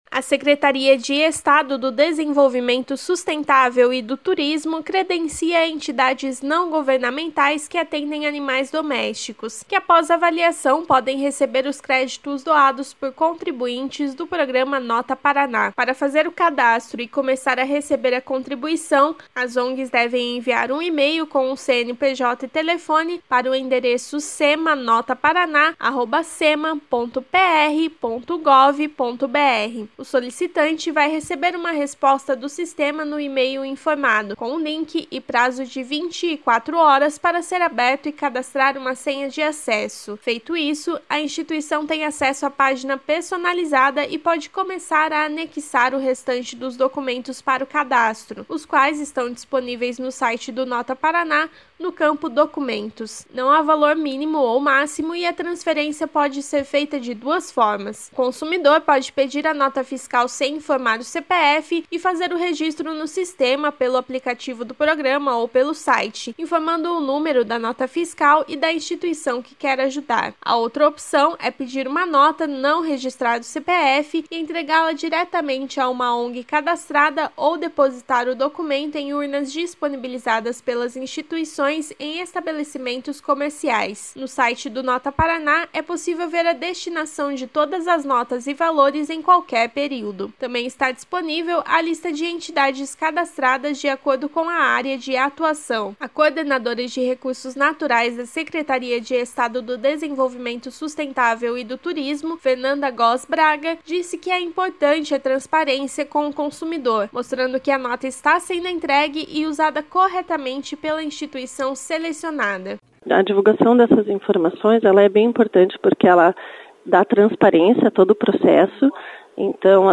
matéria